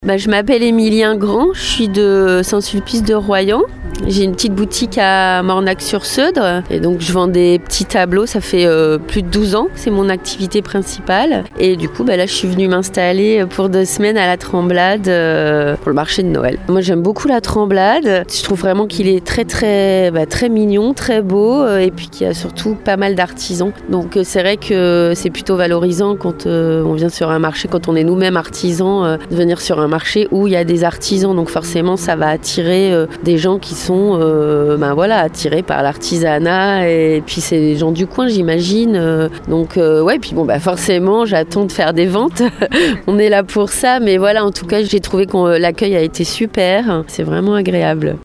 L’ouverture ce matin du Marché de Noël de La Tremblade.